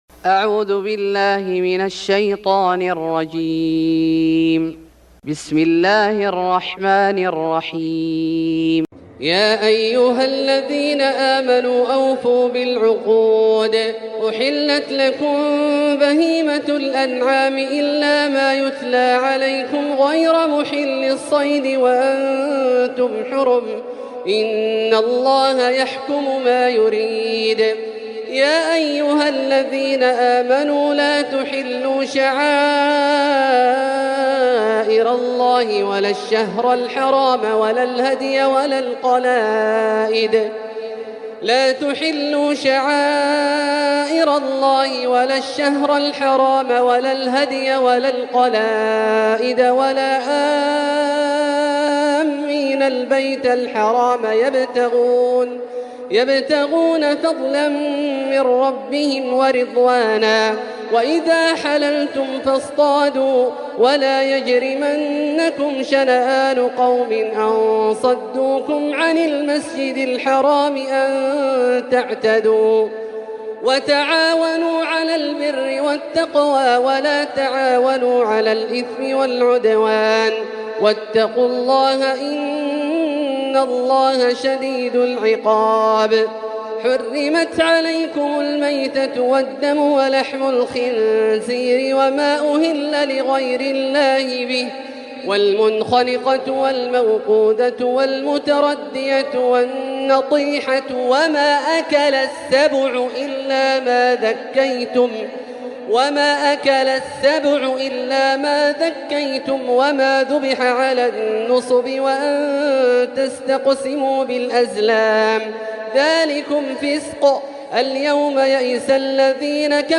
سورة المائدة Surat Al-Ma'idah > مصحف الشيخ عبدالله الجهني من الحرم المكي > المصحف - تلاوات الحرمين